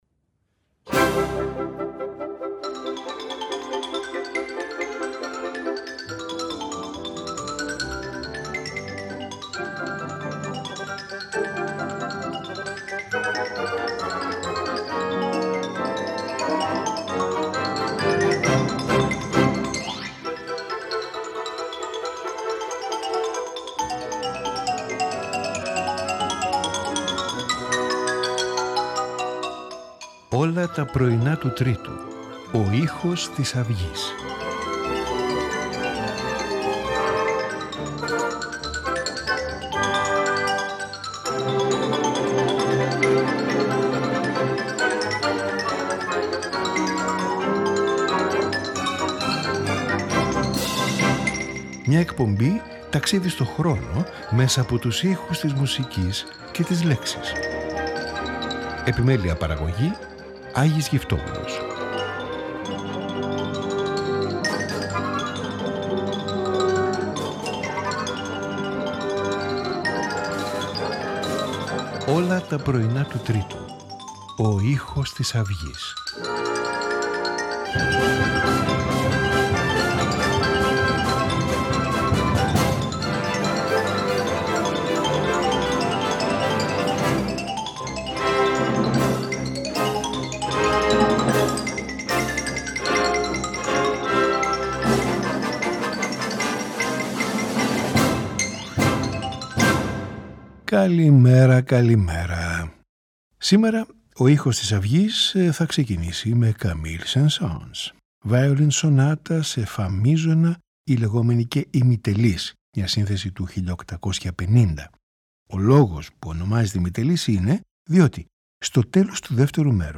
Violin Sonata